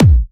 VEC3 Bassdrums Trance 59.wav